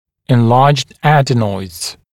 [ɪn’lɑːʤd ‘æd(ə)nɔɪdz] [en-][ин’ла:джд ‘эд(э)нойдз] [эн-]увеличенные аденоиды